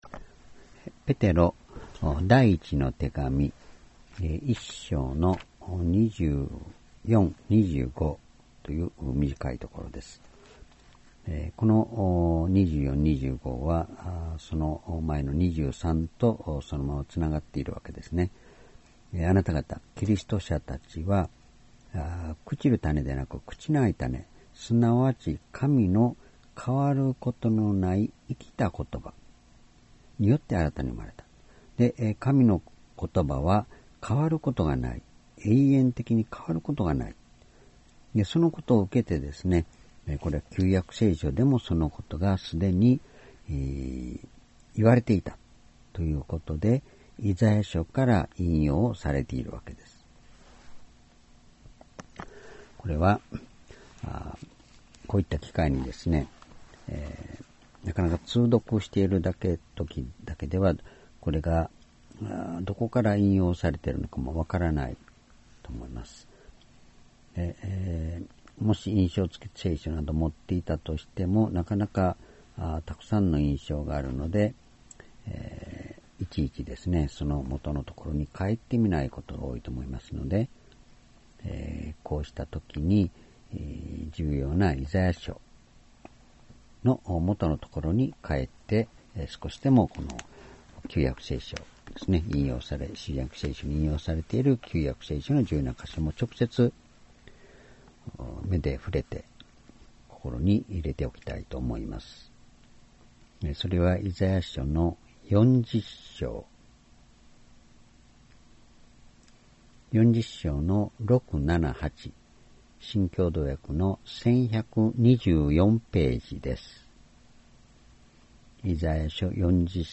主日礼拝日時 ２０１５年４月１２日 聖書講話箇所 Ⅰペテロ１の２４-２５ 「主の言葉は変ることがない」 ※視聴できない場合は をクリックしてください。